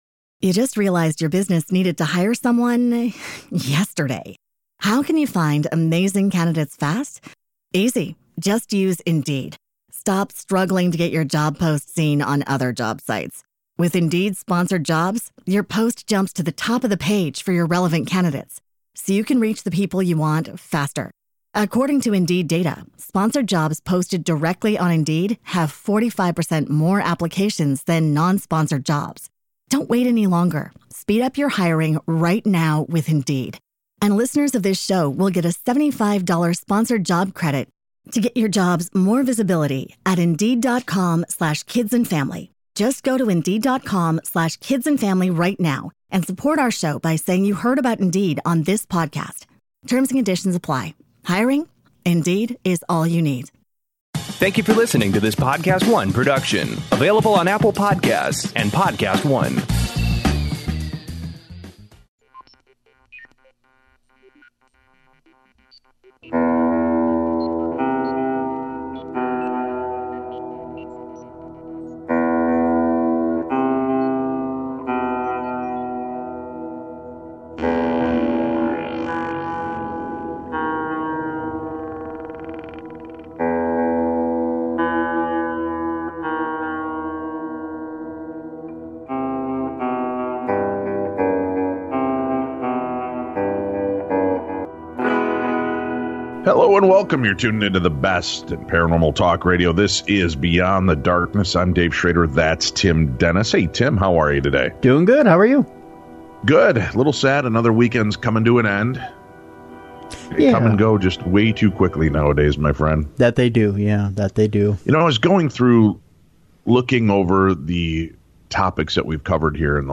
Transcript Click on a timestamp to play from that location 0:00.0 Thank you for listening to this Podcast One production available on Apple Podcasts and Podcast One 0:30.0 Hello and welcome you're tuned into the best paranormal talk radio.